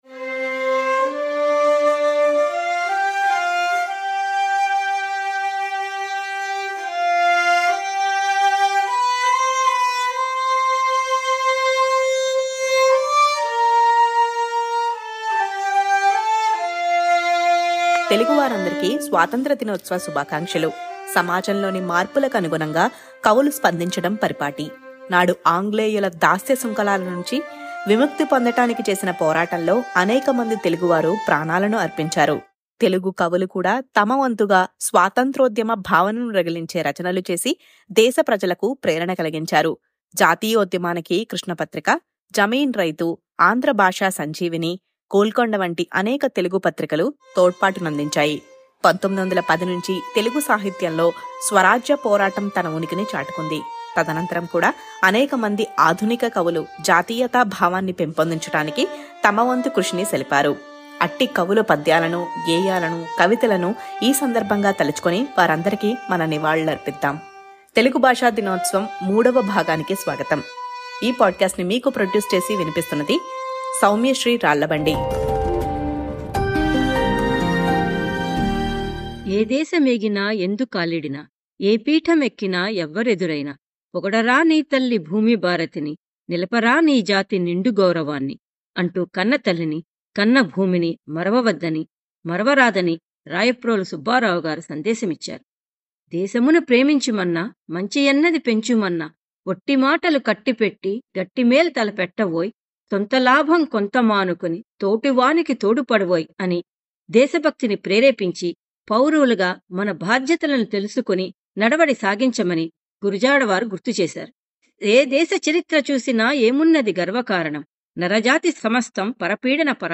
singing the song 'Jaya Jaya Priya Bharati.